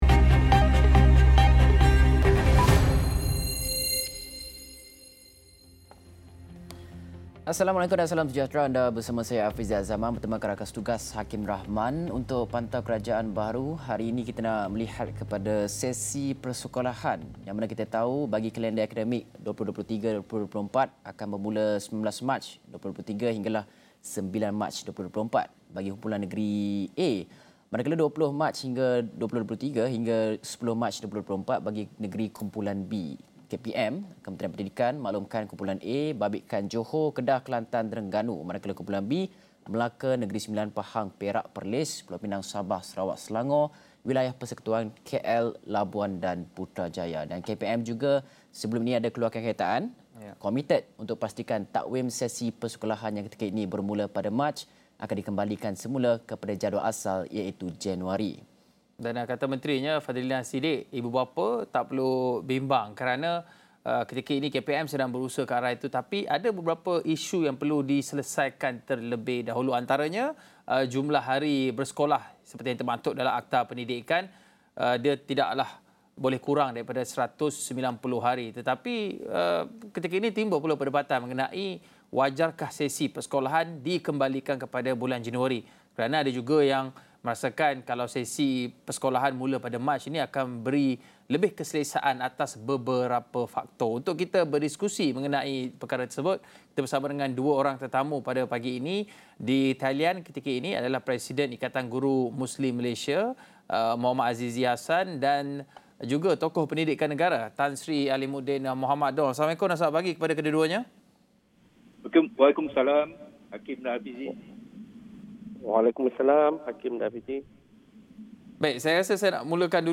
mengupas mengenai isu ini dalam diskusi setengah jam menerusi pantau kerajaan baharu.